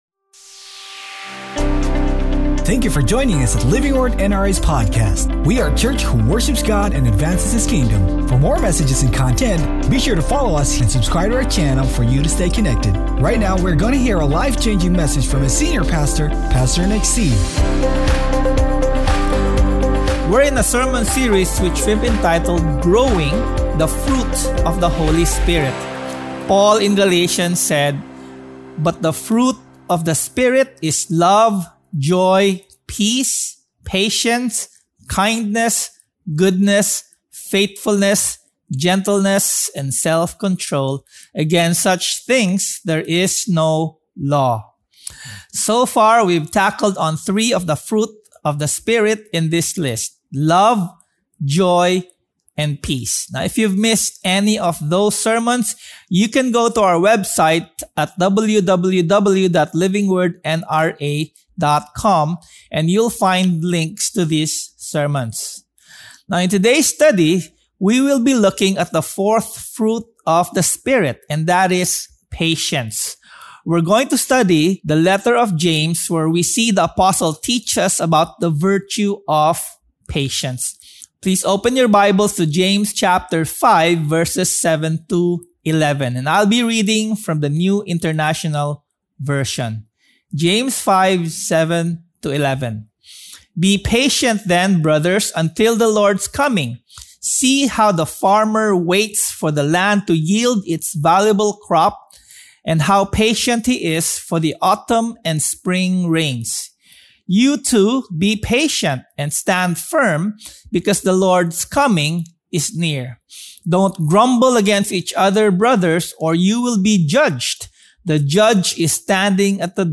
Sermon Title: THE PRACTICE OF PATIENCE